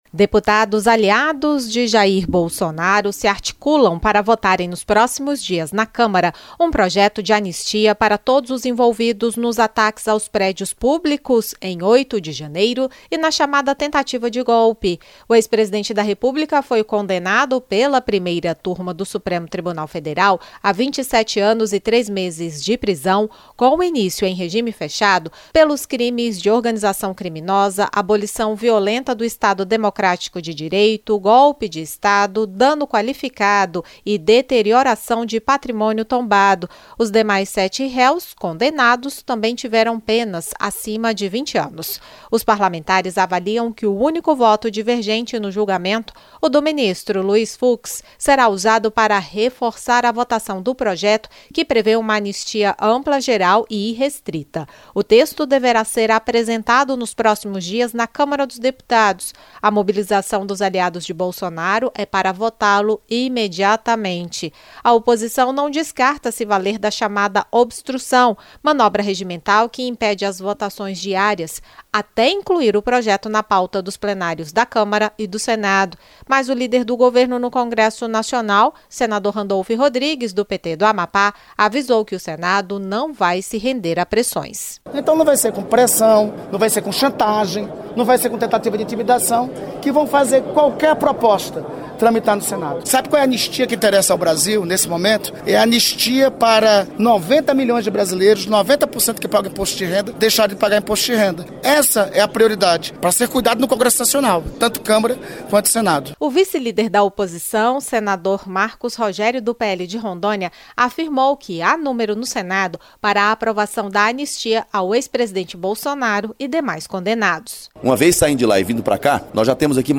O líder do governo no Congresso Nacional, senador Randolfe Rodrigues (PT-AP), avisou que o Senado não vai ceder à pressão da oposição para votar um projeto com anistia ampla, geral e irrestrita para os envolvidos no 8 de janeiro e na tentativa de golpe. Após condenação pelo Supremo Tribunal Federal de Jair Bolsonaro e outros sete réus, aliados do ex-presidente na Câmara dos Deputados querem votar nos próximos dias uma proposta para livrá-los da punição. O vice-líder da oposição, senador Marcos Rogério (PL-RO), disse que o eventual projeto da Câmara terá votos no Senado ao citar os 41 parlamentares que assinaram um pedido para abertura de processo de impeachment contra o ministro do STF, Alexandre de Moraes.